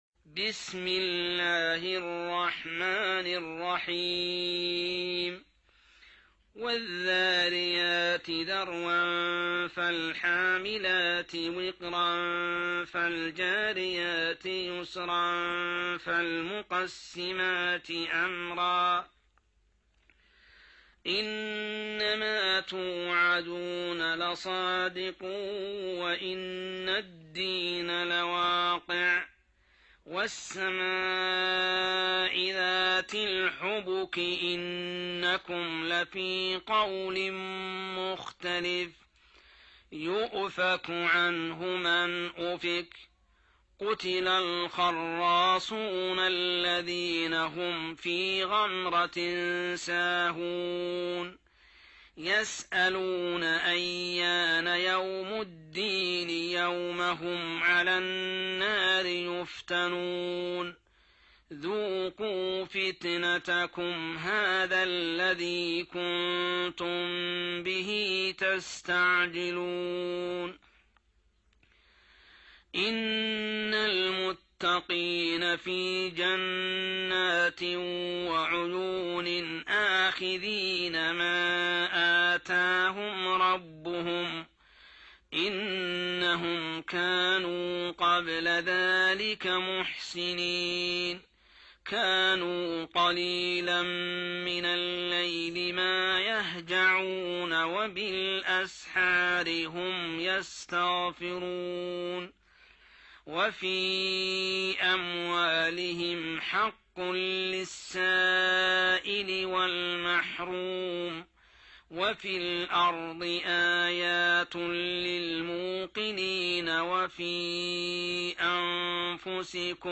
جزء الذاريات | Juz' Adh-Dhariyat > المصحف المرتل للشيخ محمد السبيل > المصحف - تلاوات الحرمين